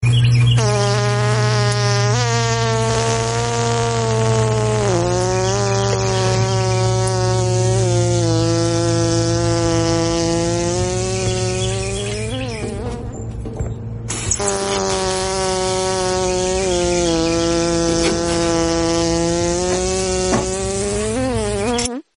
fart Meme Sound Effect